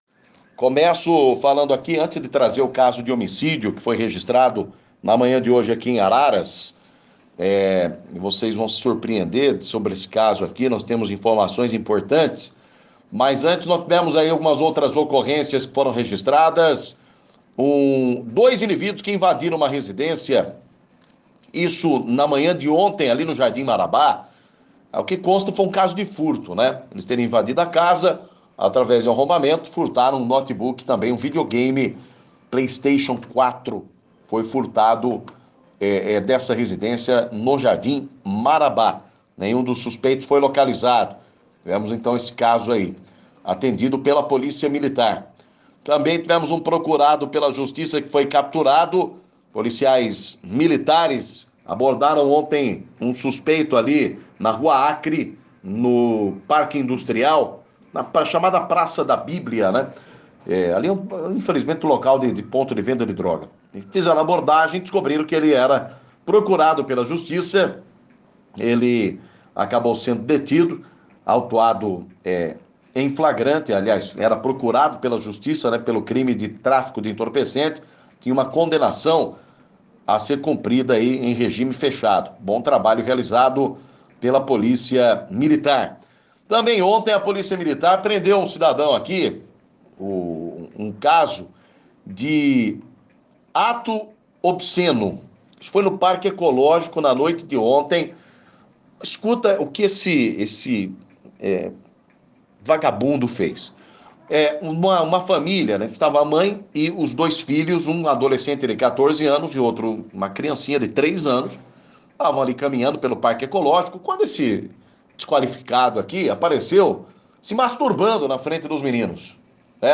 Destaque Polícia